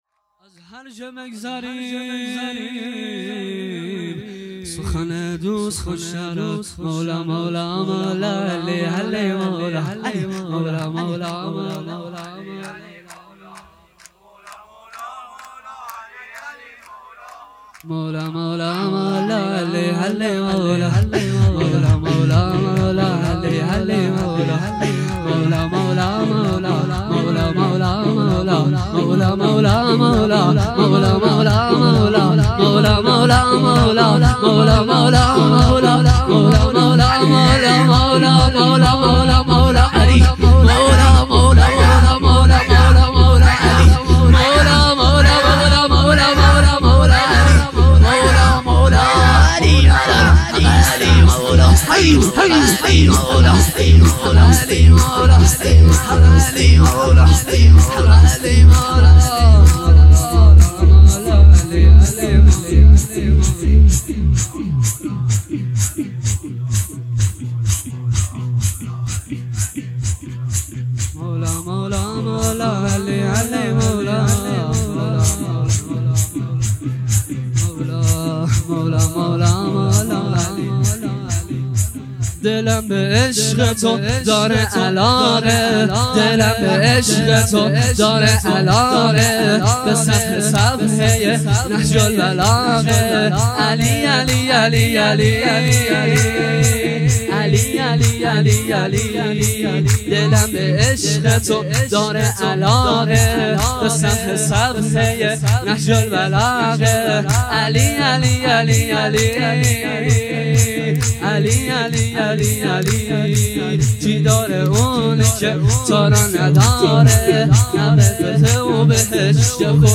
مراسم یادبود سالگرد سردار سلیمانی 1402